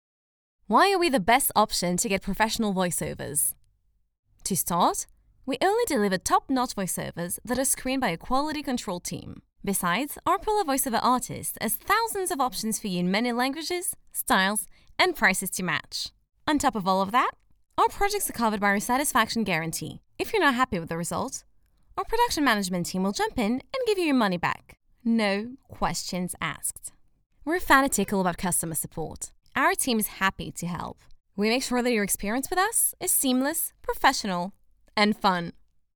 I work on Pro Tools First and Adobe Audition with a Rode NT1-A mic.